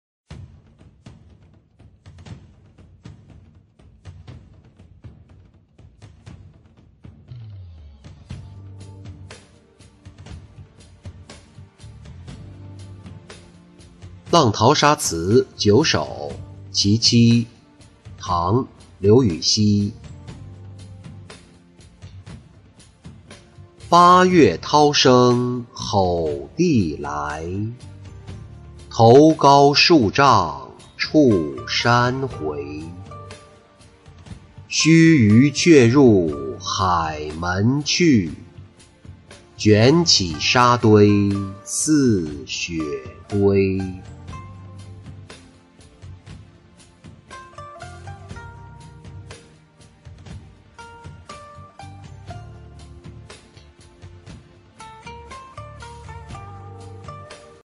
浪淘沙·其七-音频朗读